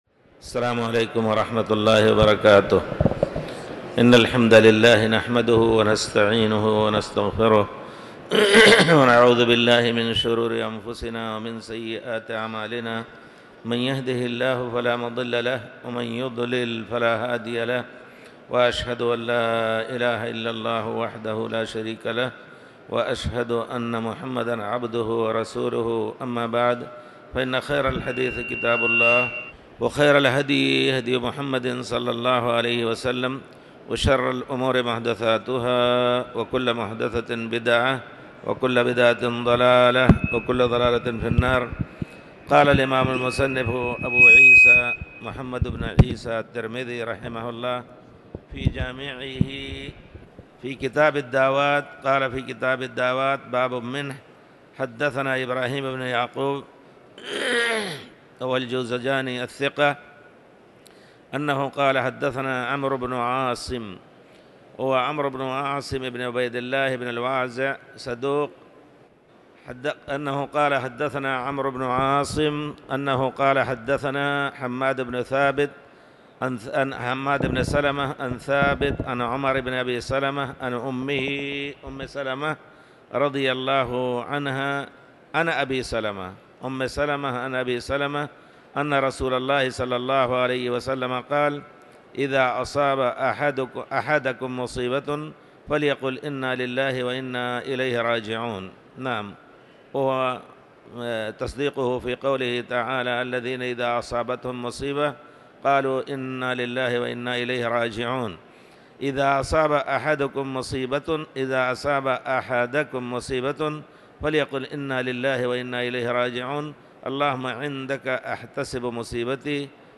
تاريخ النشر ١١ جمادى الآخرة ١٤٤٠ هـ المكان: المسجد الحرام الشيخ